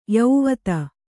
♪ yauvata